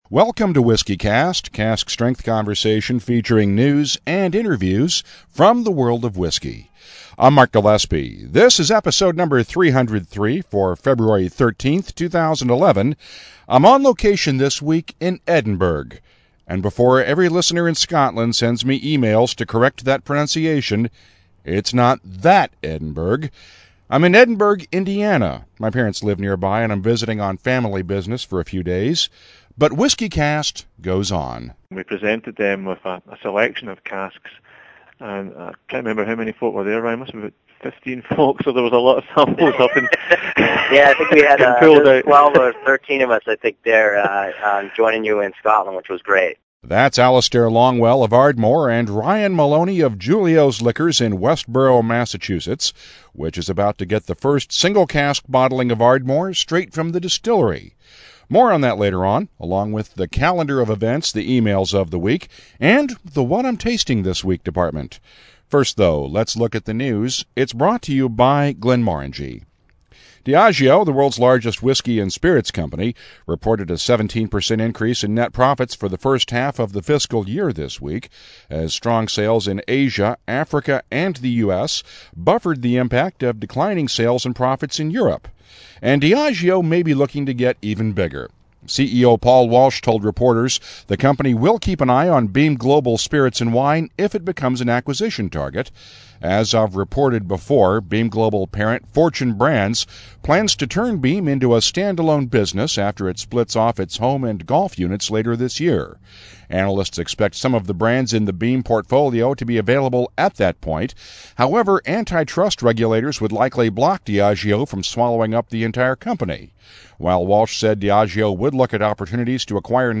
This week’s episode comes from Edinburgh…no, not THAT Edinburgh.